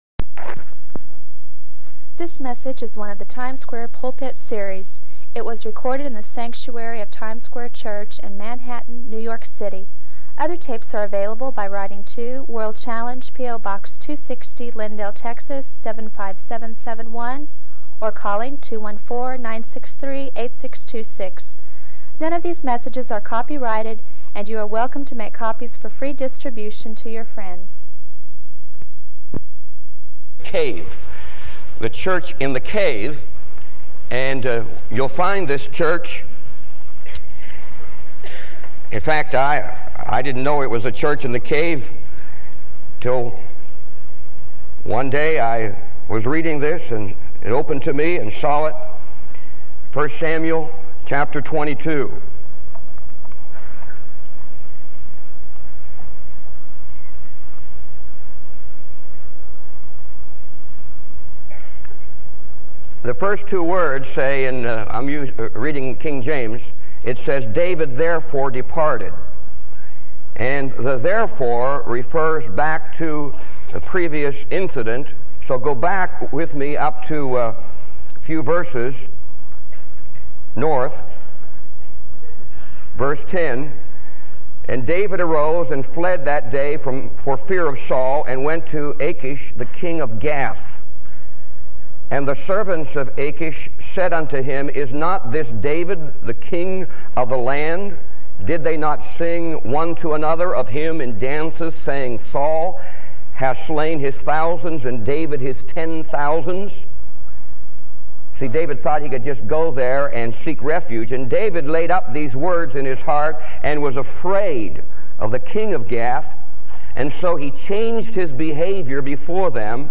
This sermon offers encouragement for those facing spiritual struggles and a blueprint for enduring faithfulness.
It was recorded in the sanctuary of Times Square Church in Manhattan, New York City.